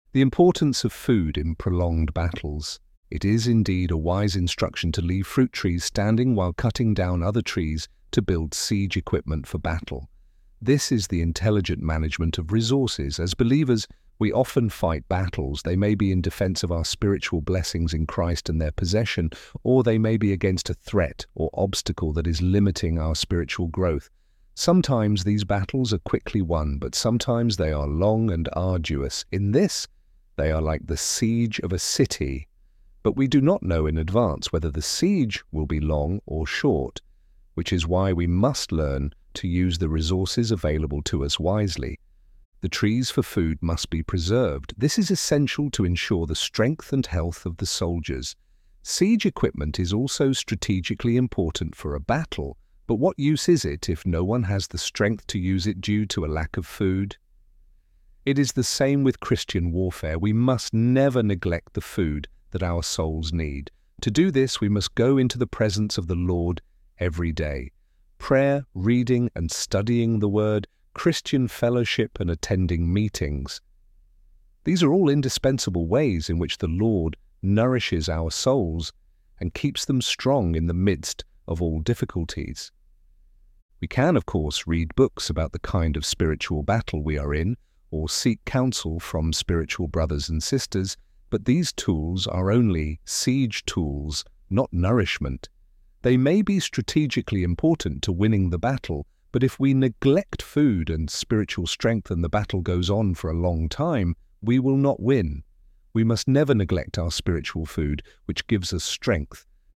ElevenLabs_Do_not_Destroy_the_Fruit_Trees.mp3